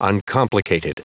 Prononciation du mot uncomplicated en anglais (fichier audio)
Prononciation du mot : uncomplicated